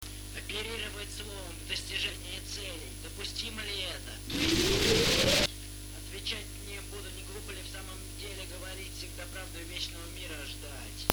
голос, cаунд-дизайн